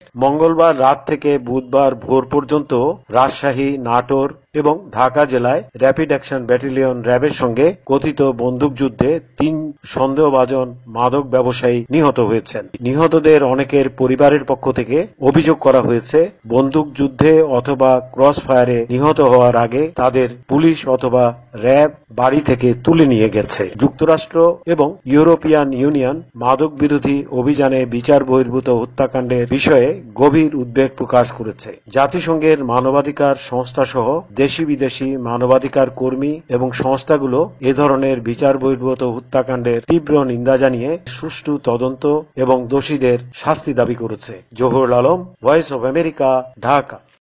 ঢাকা থেকে রিপোর্ট পাঠিয়েছেন